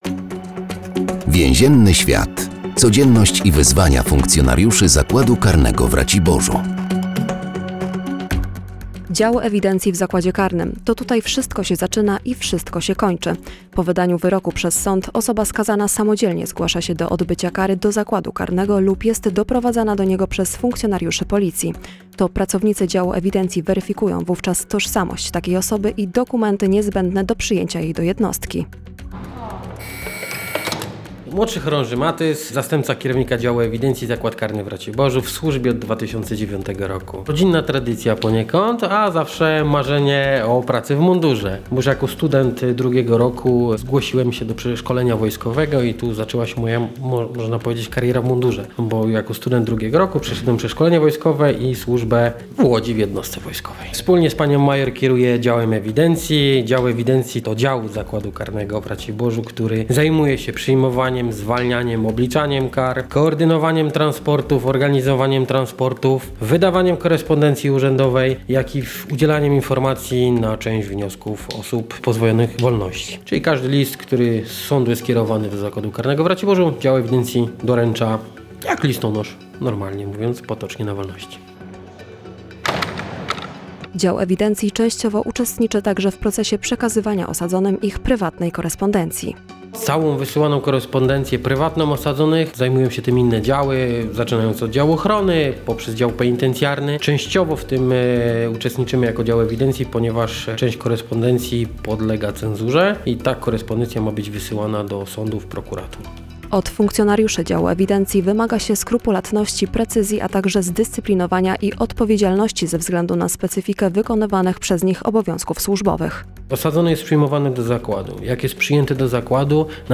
W każdym odcinku audycji “Więzienny świat” funkcjonariusze zakładu karnego w Raciborzu opowiadają o specyfice swojej pracy.